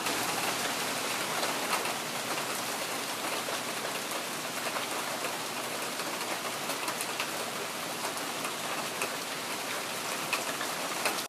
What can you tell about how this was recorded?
The Seattle sudden downpour. »